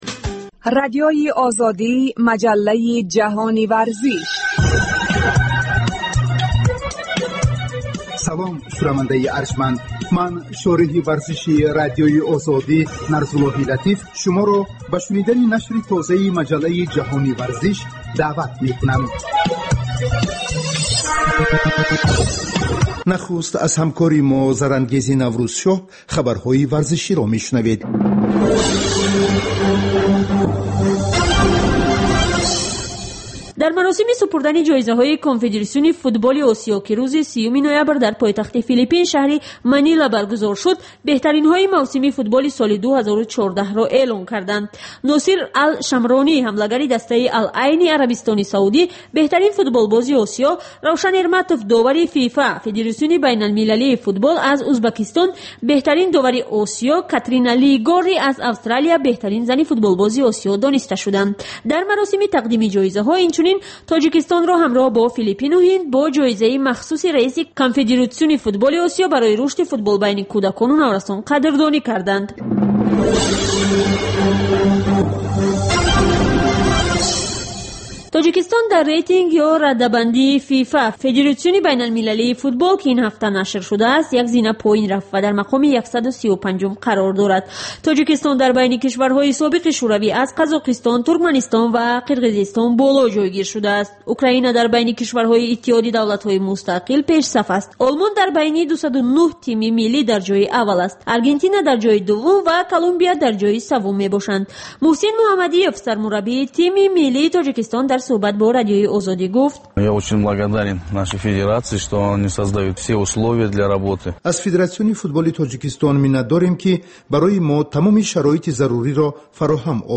Варзиш дар Тоҷикистон ва ҷаҳон. Маҷаллаи вижаи дӯстдорони ахбор ва гузоришҳои варзишии Радиои Озодӣ.